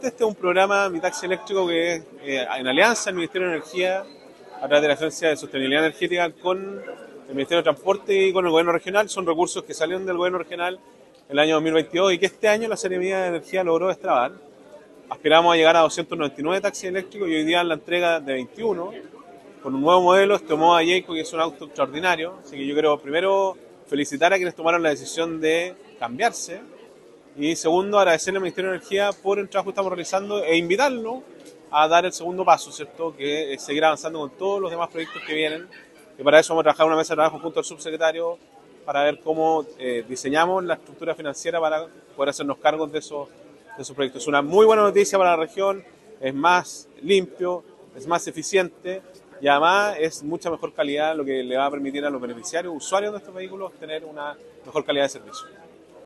Junto con valorar el trabajo interinstitucional, el gobernador Sergio Giacaman subrayó el impacto social y ambiental de esta iniciativa.